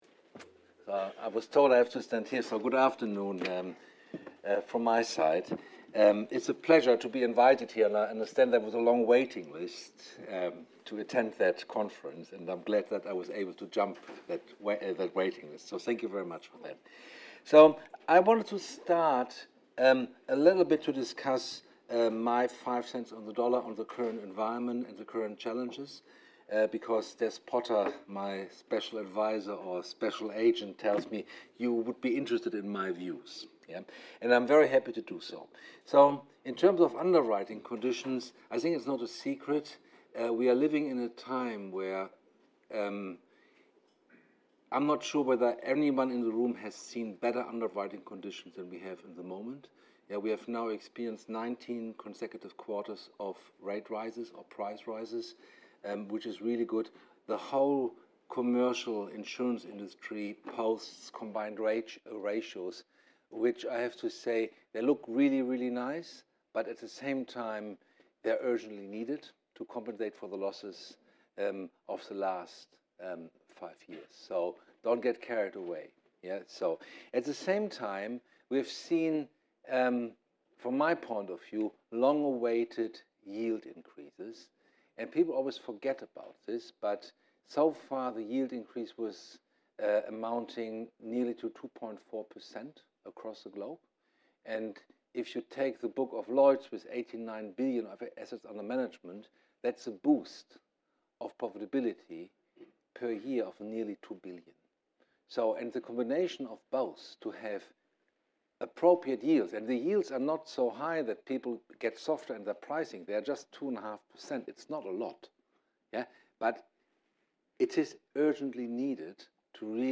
This was the fifth session of the day from our Artemis London 2022 conference, held on September 6th, 2022.